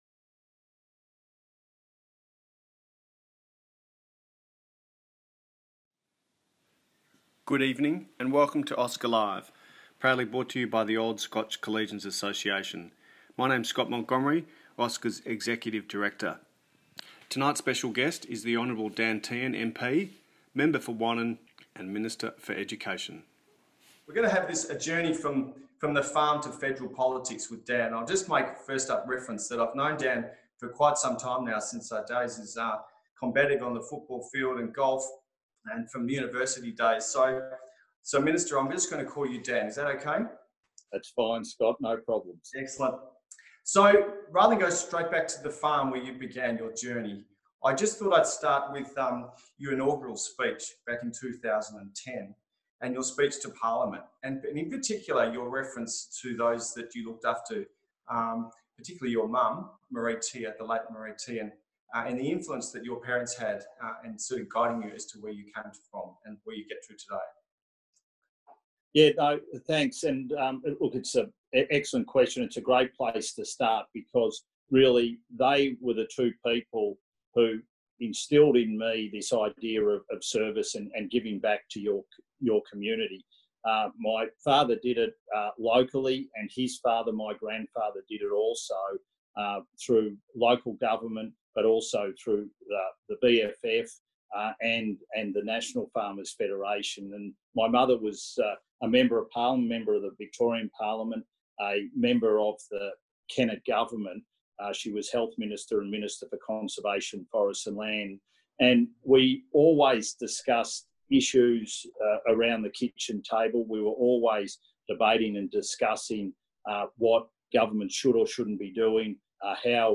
Dan-Tehan-OSCA-Live-Webinar-Final-AUDIO.mp3